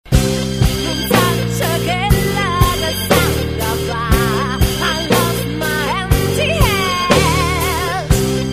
voce e campioni
pianoforte, campionatore e programmazione
batteria, chitarre trattate e campioni
bassi elettronici e contrabasso